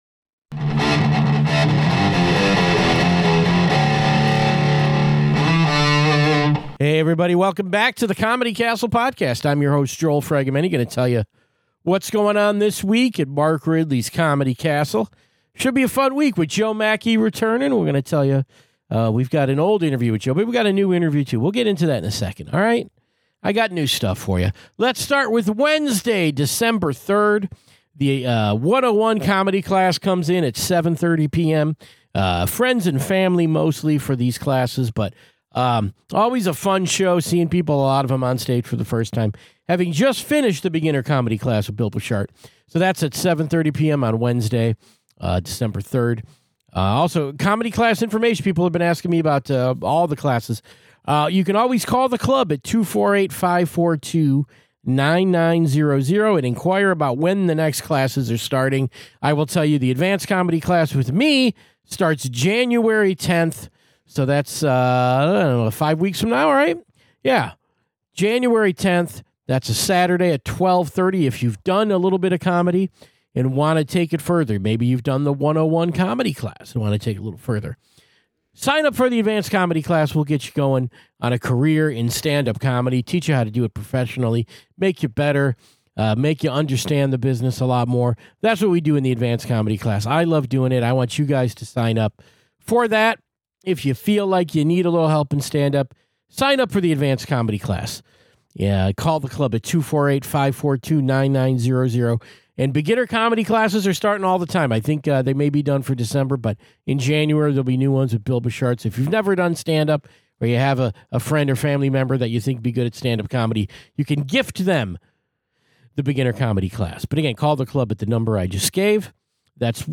He emcees this weekend and joined us for a chat on this show.